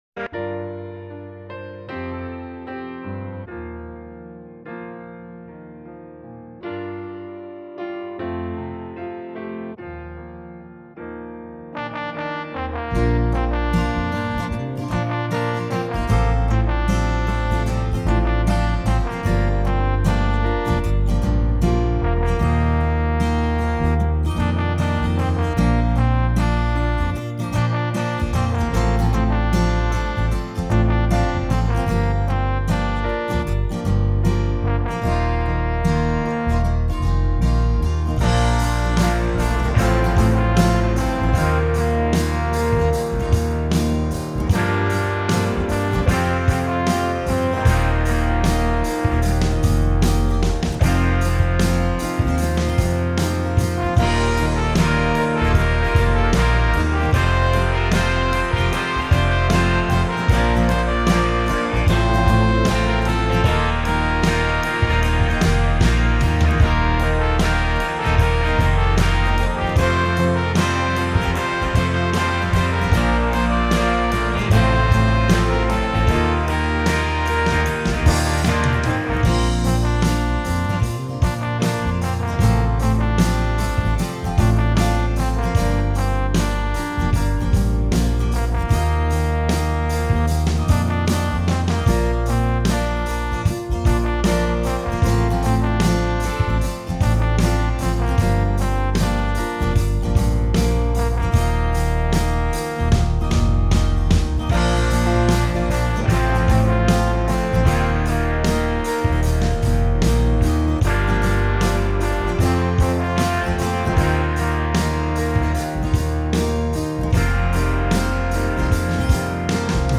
For all that it is a pretty good pop song.